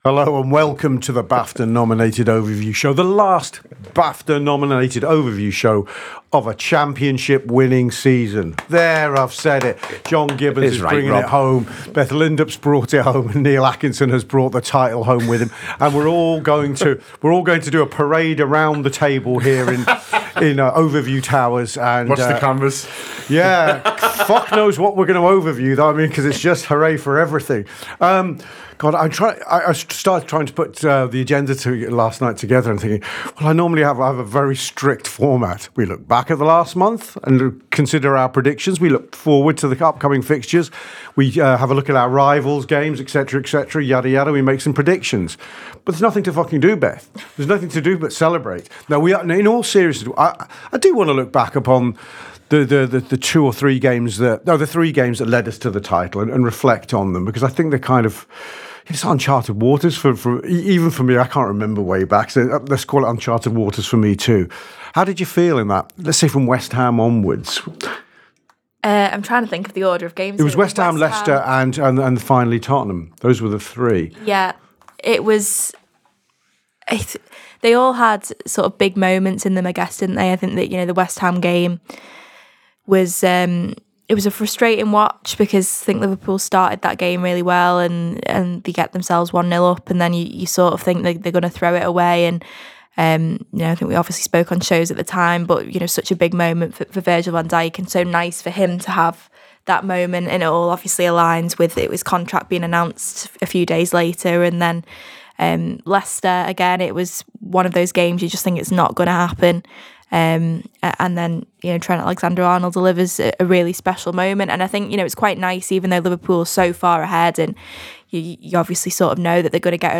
Below is a clip from the show – subscribe for more on Liverpool’s season